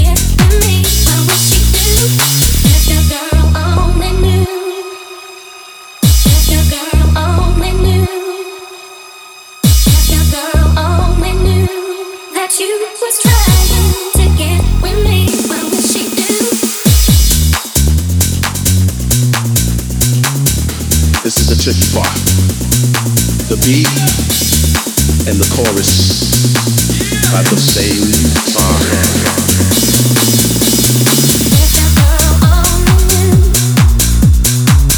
Жанр: Танцевальные / Хаус
Dance, House